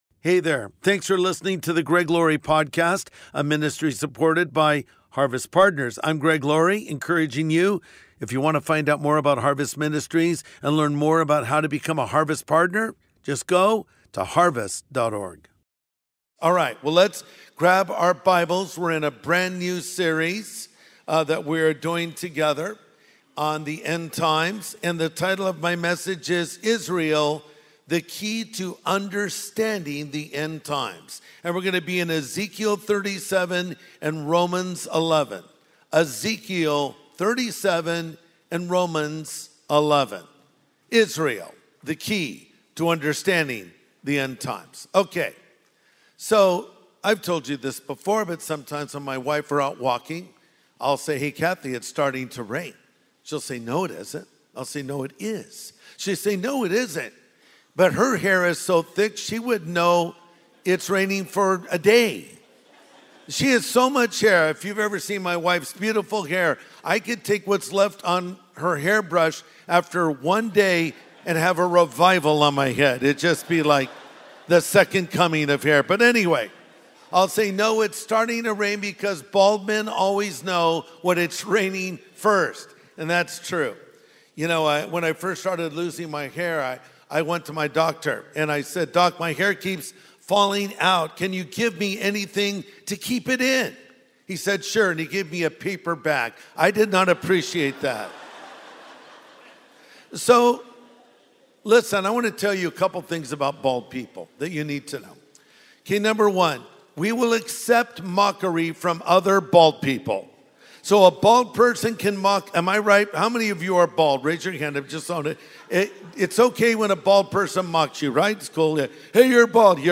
Although Israel plays a significant role in Bible prophecy, there is still a lot misunderstood. Pastor Greg Laurie provides clarity on the topic. Learn about God's plan and how this nation plays a part in it.